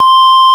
FARFISA4  C5.wav